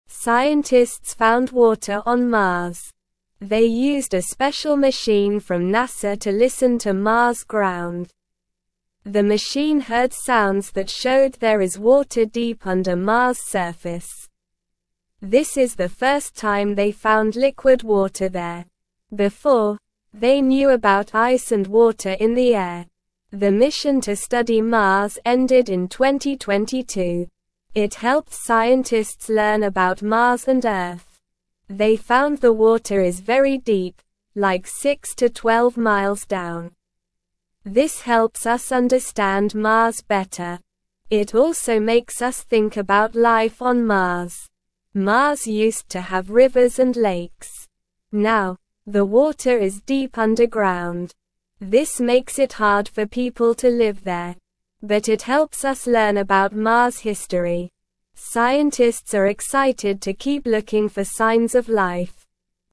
Slow
English-Newsroom-Beginner-SLOW-Reading-Water-Found-on-Mars-Deep-Underground-Excites-Scientists.mp3